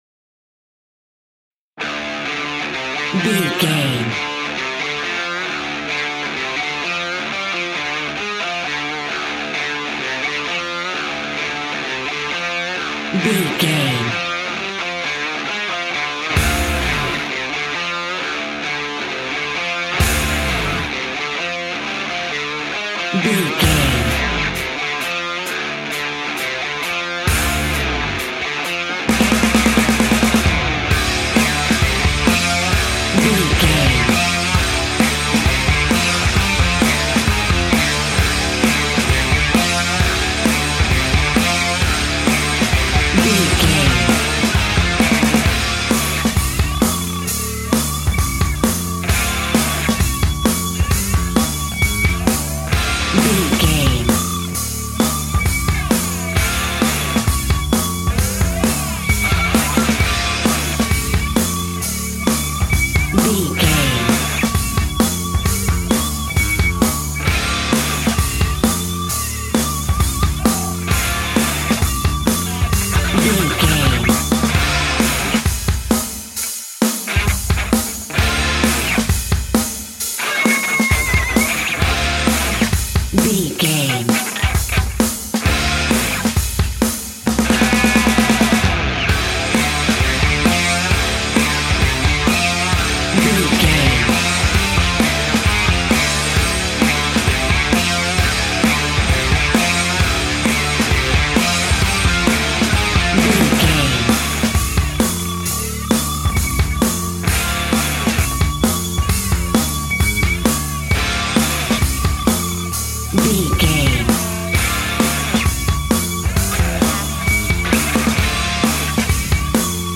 Aeolian/Minor
D
hard rock
heavy metal
instrumentals
Heavy Metal Guitars
Metal Drums
Heavy Bass Guitars